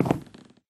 Sound / Minecraft / step / wood5